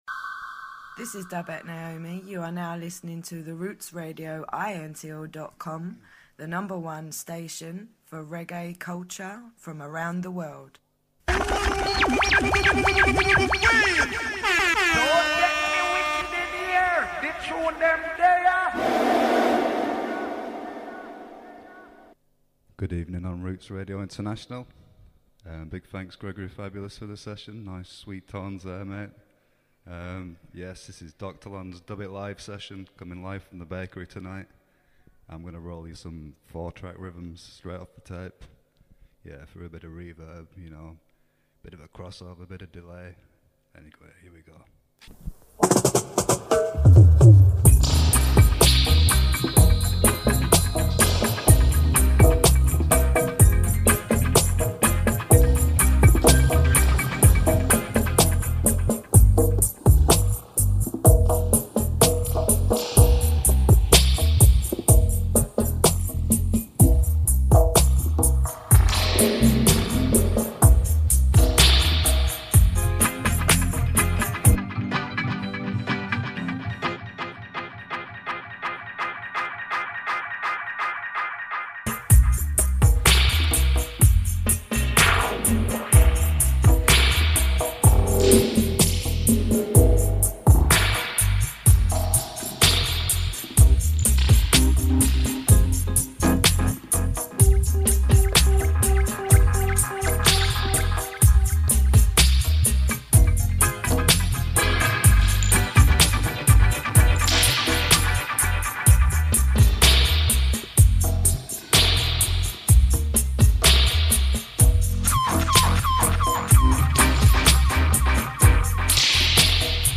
Live radio session
Edited down to just the live dub mixing.